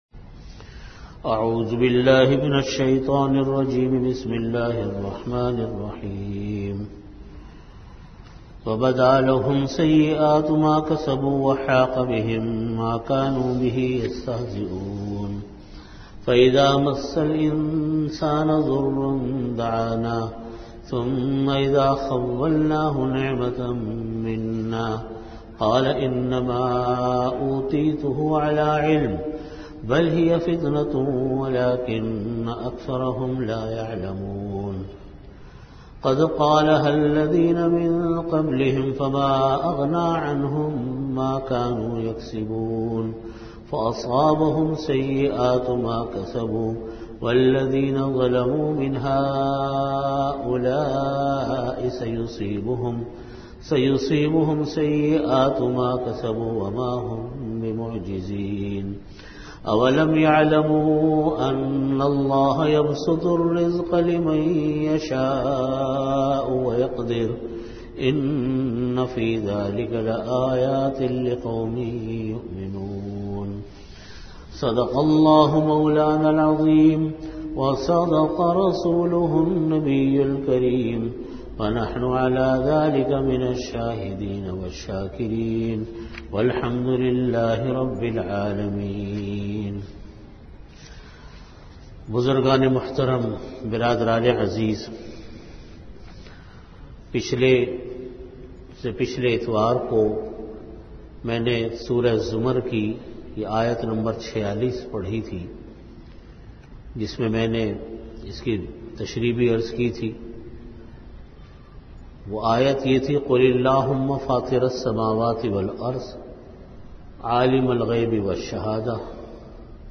Audio Category: Tafseer
Venue: Jamia Masjid Bait-ul-Mukkaram, Karachi